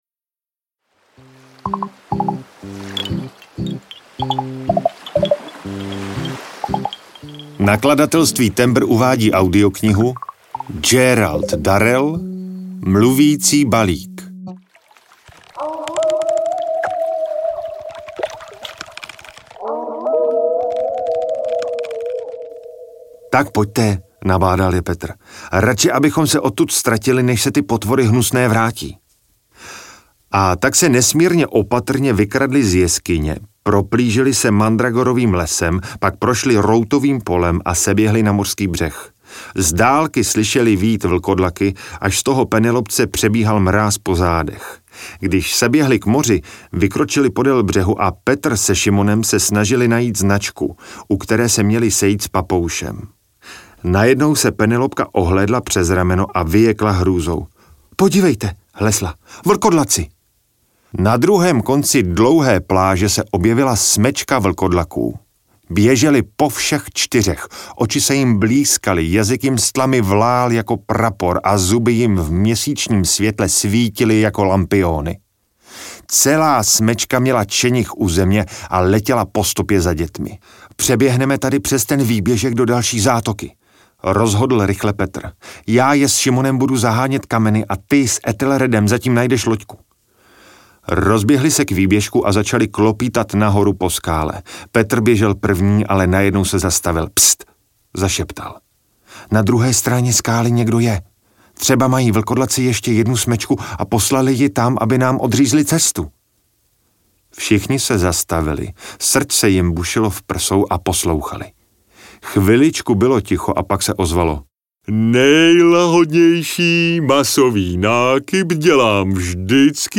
Mluvící balík audiokniha
Ukázka z knihy